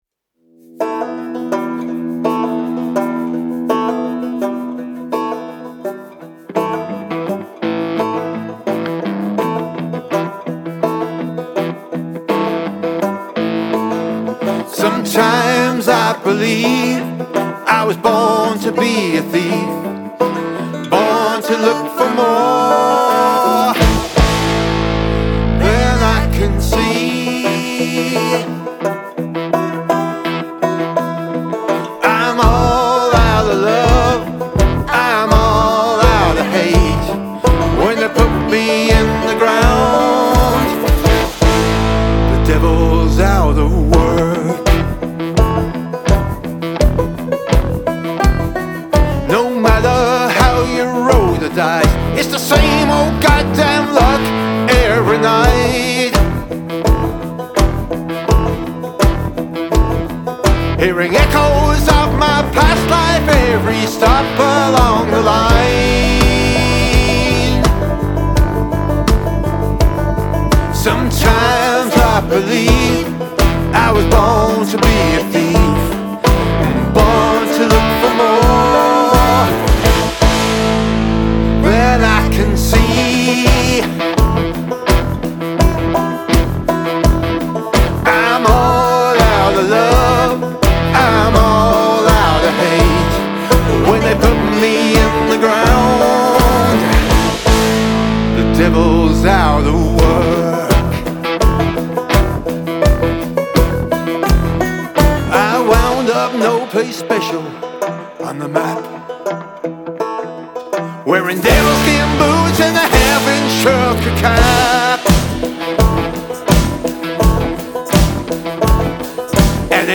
Male Vocal, Guitar, Banjo, Bass Guitar, Synth, Drums